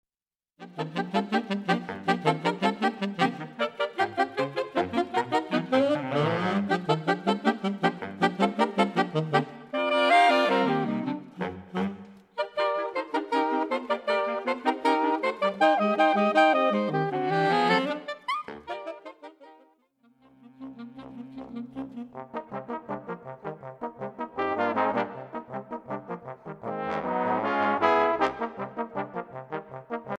Voicing: Drums